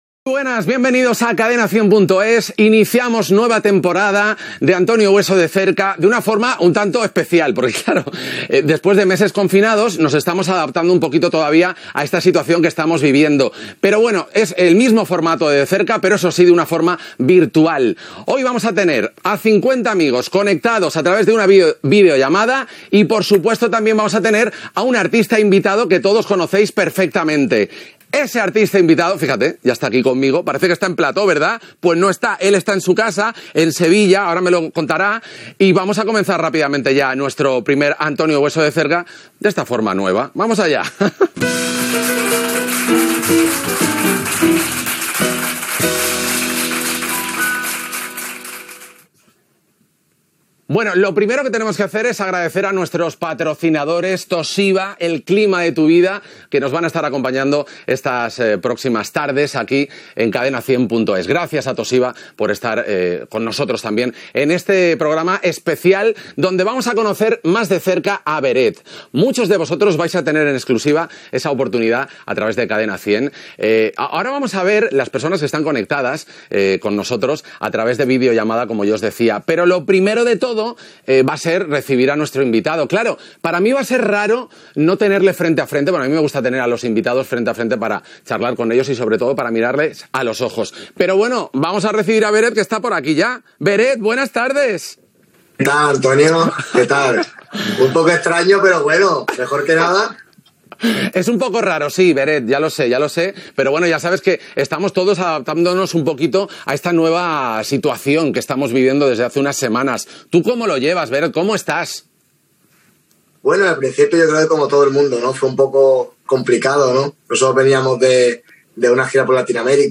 Presentació de la primera edició virtual del programa degut al confinament de la pandèmia de la Covid 19. Presentació i entrevista al cantant Beret (Francisco Javier Álvarez Beret).
Entreteniment